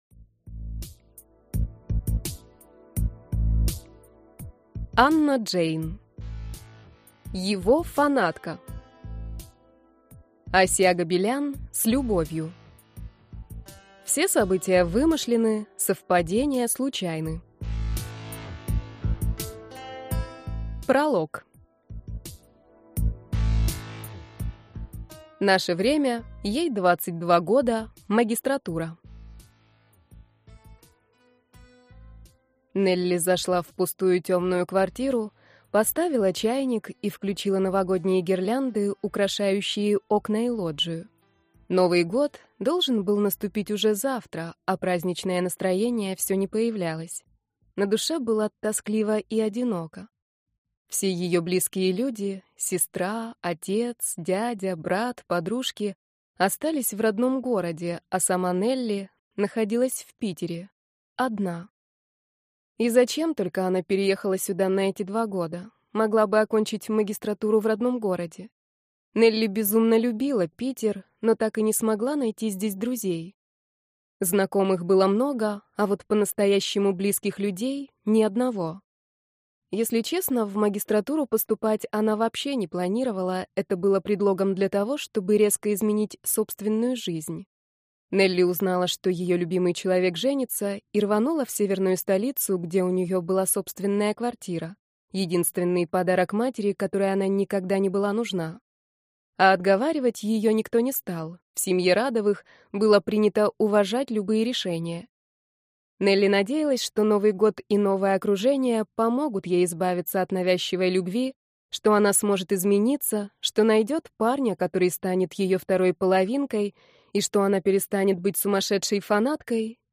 Аудиокнига Его фанатка | Библиотека аудиокниг